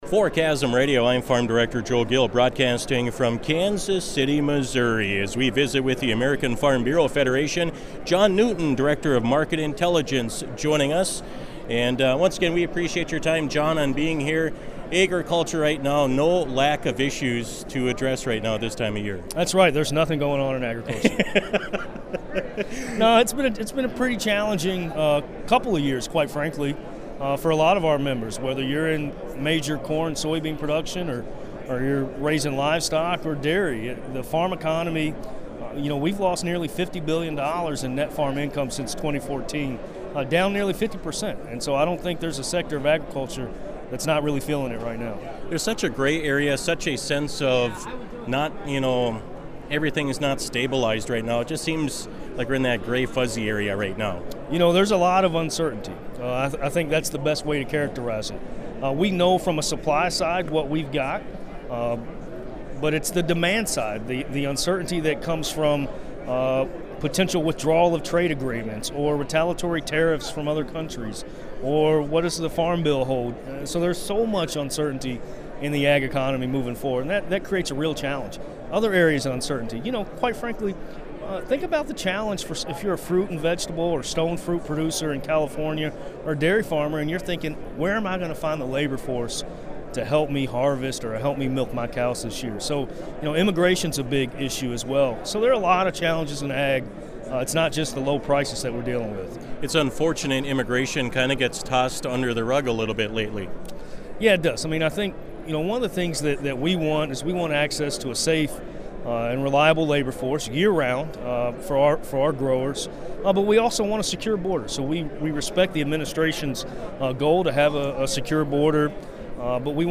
KASM had the wonderful opportunity to interview over 30 of the leading agricultural organizations at the 74th Annual National Association of Farm Broadcasting Convention in Kansas City, MO November 8th-10th – take a listen to our coverage: